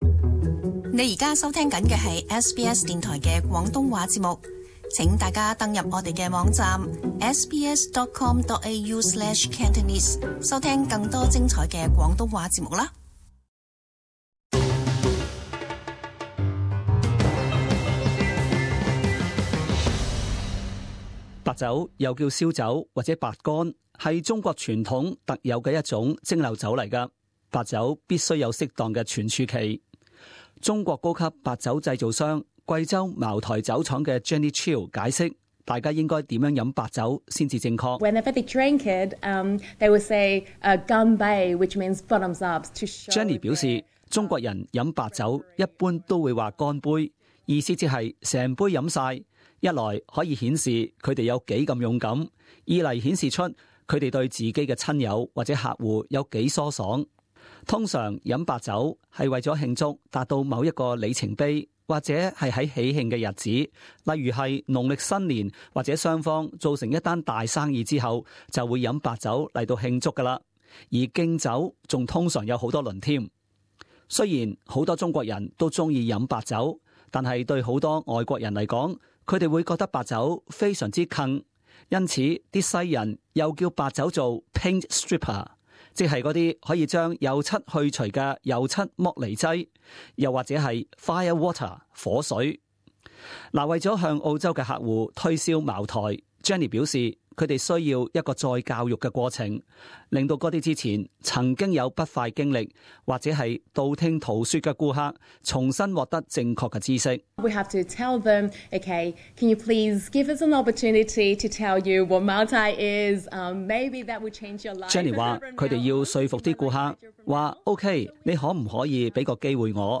時事報導